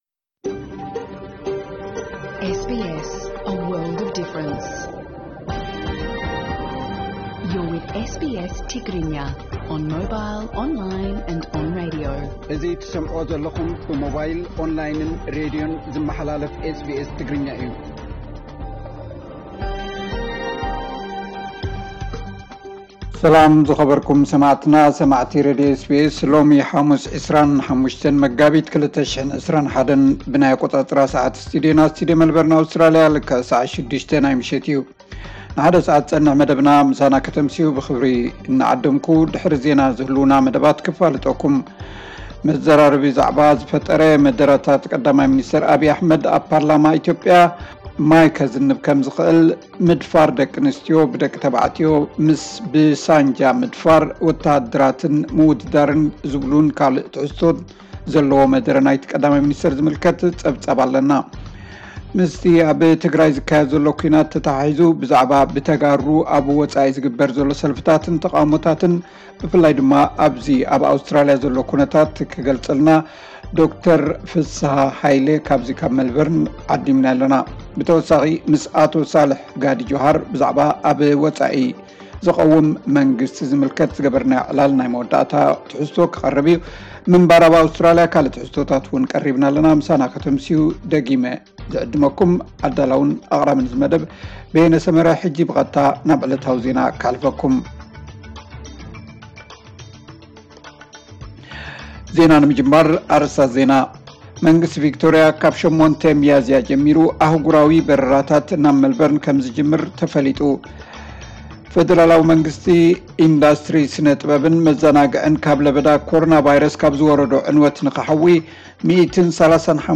ዕለታዊ ዜና SBS ትግርኛ 25 መጋቢት 2021